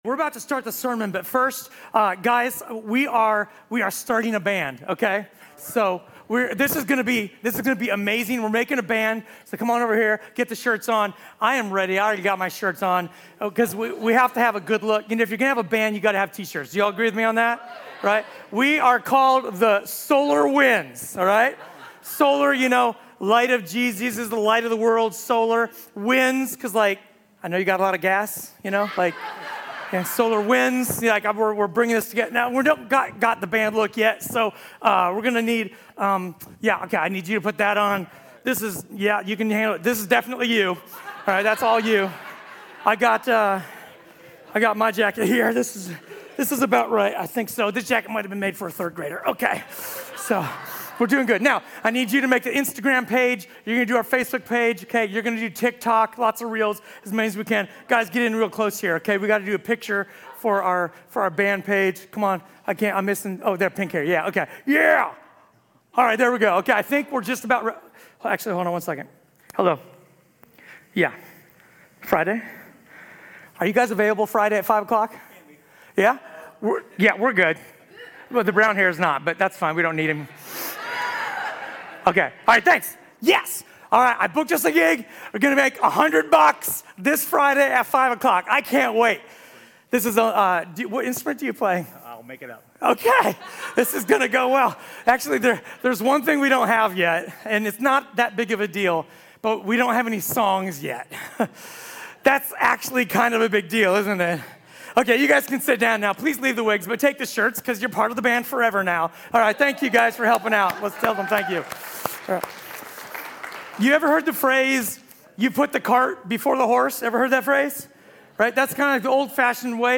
A sermon from the series "The Money House."